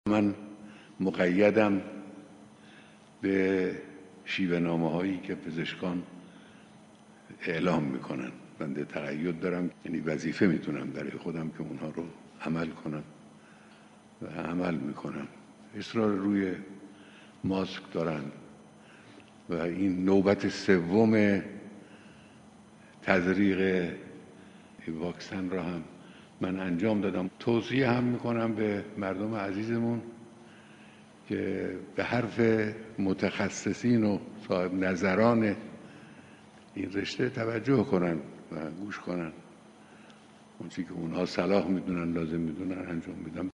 ایشان صبح امروز در ابتدای دیدار فرماندهان نیروی هوایی و پدافند هوایی ارتش تاکید کردند: من مقیدم به شیوه‌نامه‌هایی که پزشکان اعلام می‌کنند.